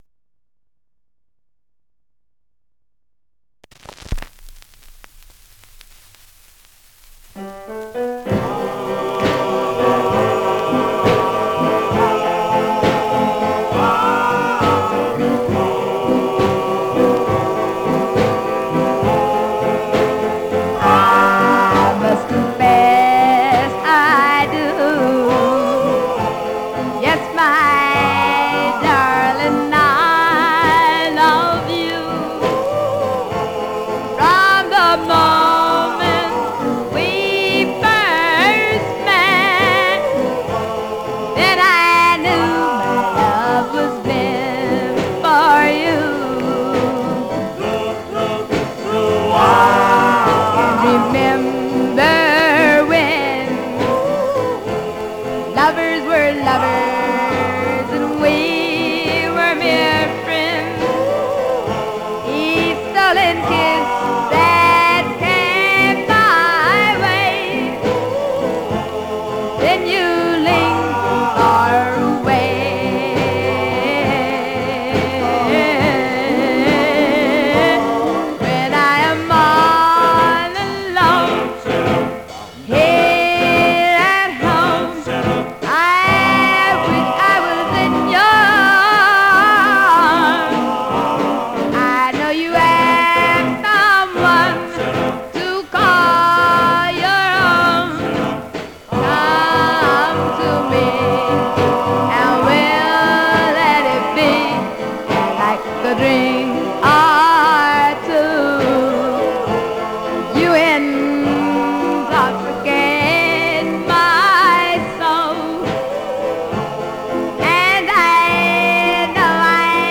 Surface noise/wear
Mono
Male Black Group